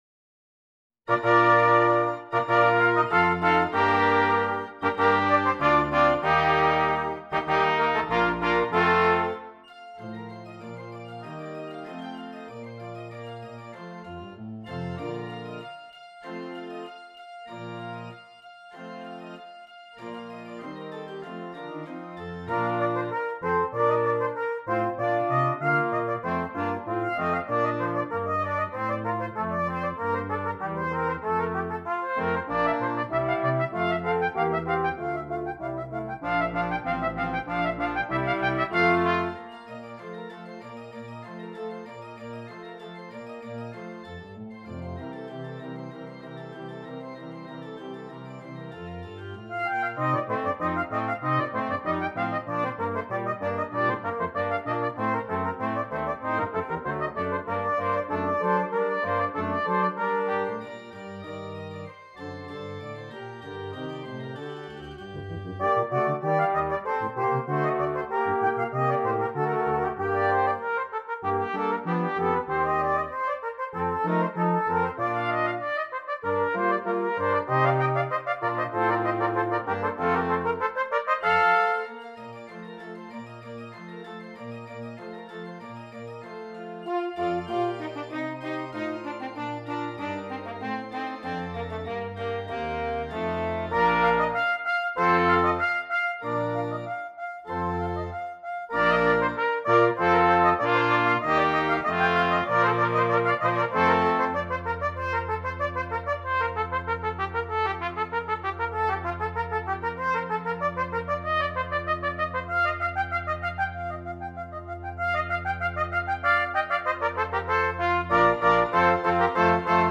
Brass Quintet and Organ